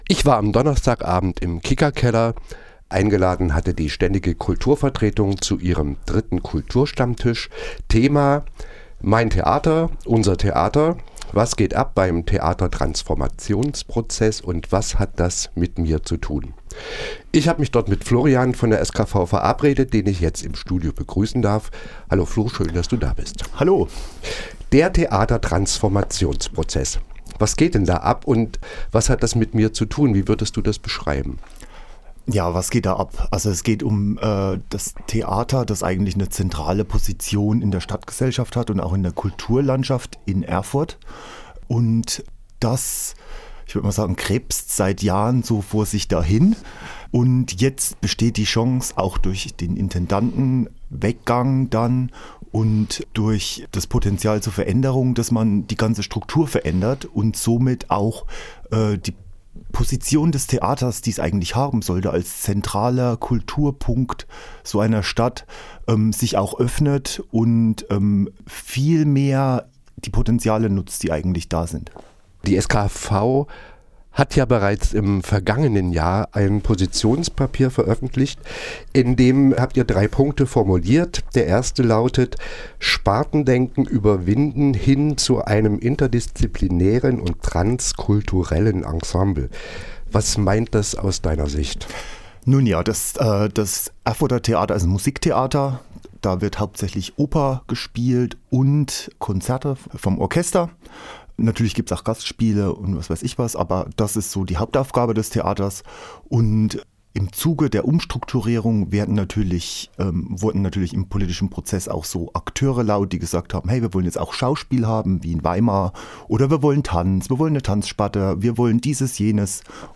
Interview SKV kor.mp3